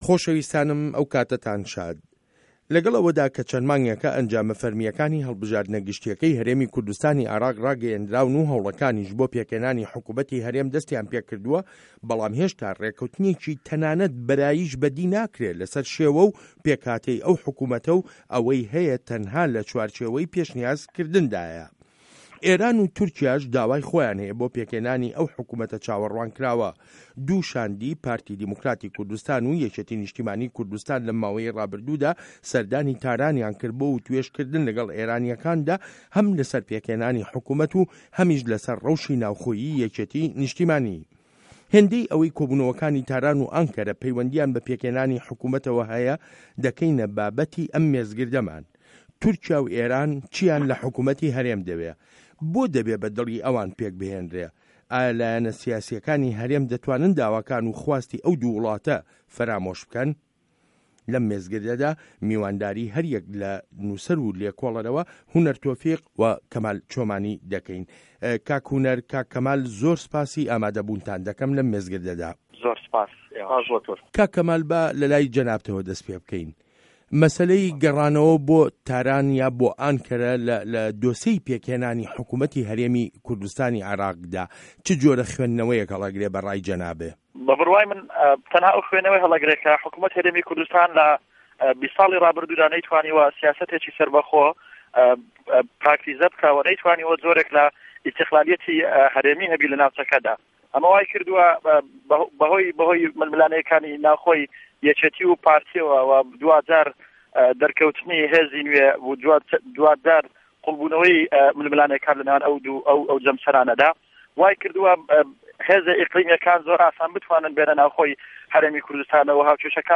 مێزگرد: پێکهێنانی حکومه‌تی هه‌رێم له‌ ڕوانینی ئێران و تورکیاوه